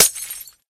glass1.ogg